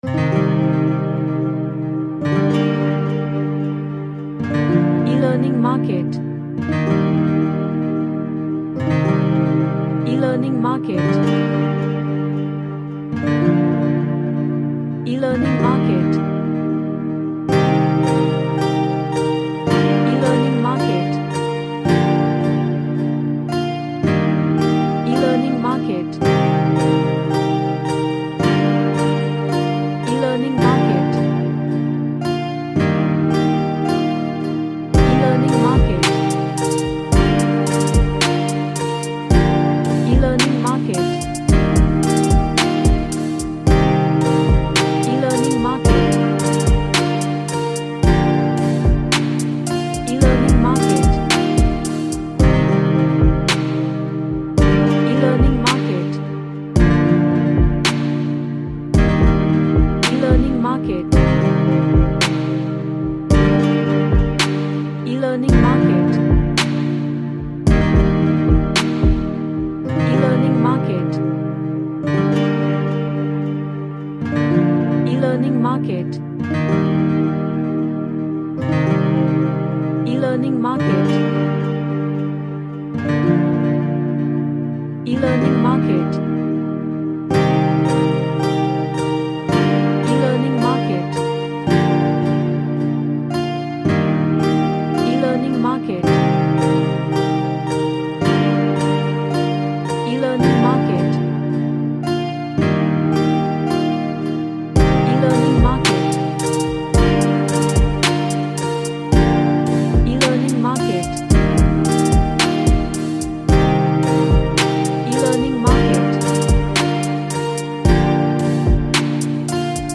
An trap track featuring acoustic elements.
Emotional